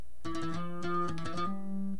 Las letras entre paréntesis son los requintos que están al final!!!